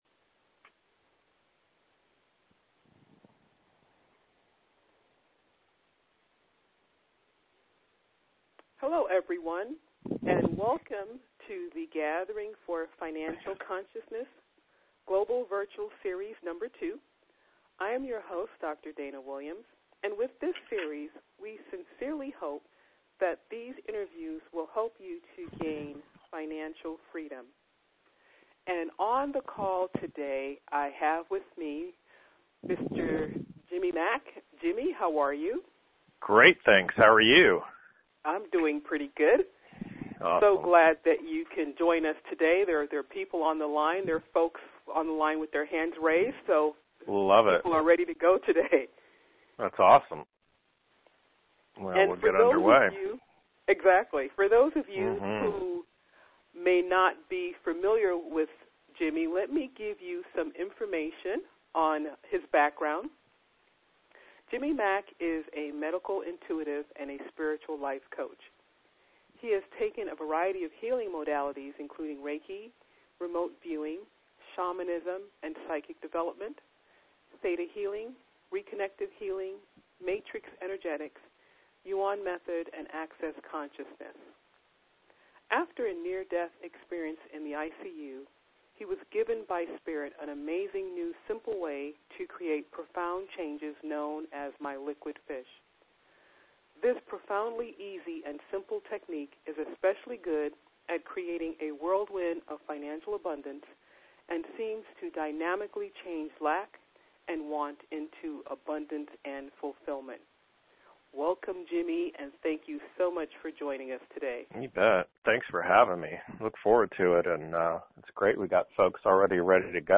I’ve been busy chatting things up with many new friends and spreading the word and the healing with MyLiquidFish® this month and this replay is a special one… all who listen to this call from last week’s Financial Consciousness for Conscious People Gathering will benefit from many, many important clearings!